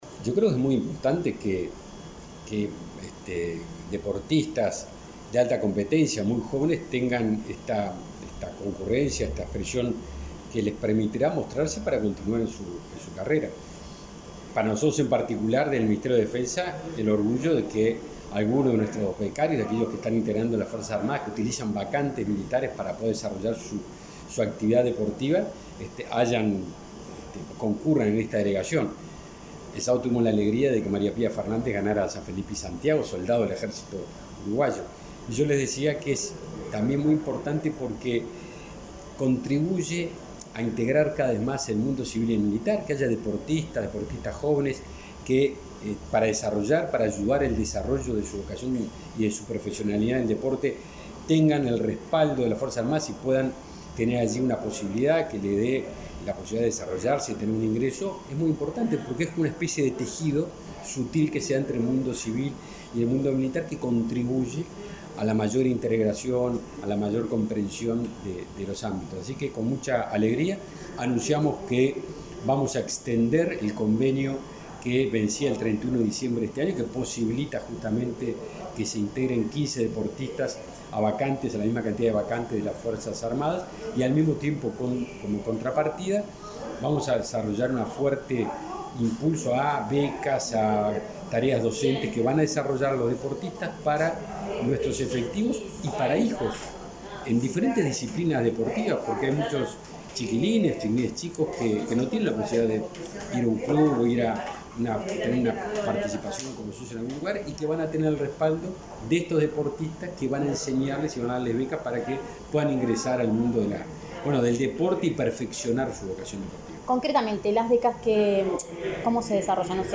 Declaraciones del ministro de Defensa, Javier García
Declaraciones del ministro de Defensa, Javier García 15/11/2021 Compartir Facebook X Copiar enlace WhatsApp LinkedIn El ministro de Defensa, Javier García, participó de la entrega del pabellón nacional a los competidores de los primeros Juegos Panamericanos de la Juventud, que se disputarán en Cali, Colombia.